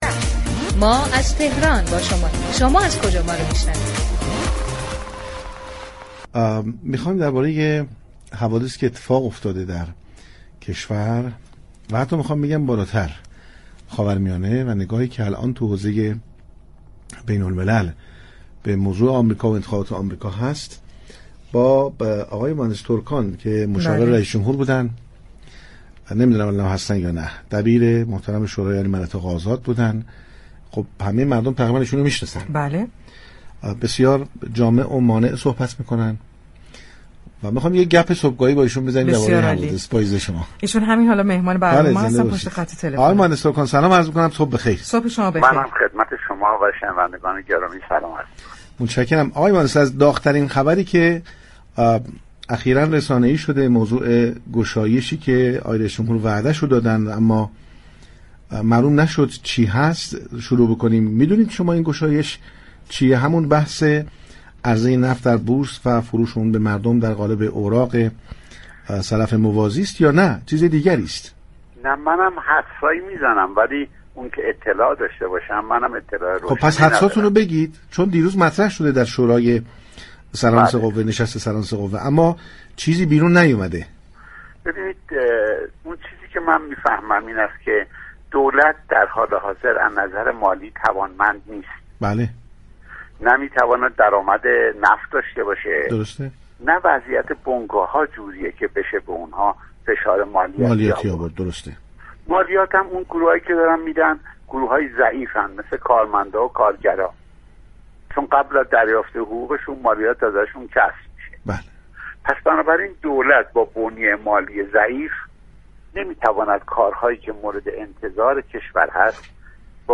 مهندس اكبر تركان، مشاور رییس جمهور درباره تحولات اقتصادی كشور، انتخابات ریاست جمهوری امریكا و ارتباط آن با اتفاقات اخیر لبنان و تاثیر آن بر نفوذ راهبردی ایران در این كشور و انتخابات ریاست جمهوری 1400 ایران با پارك شهر گفتگو كرد.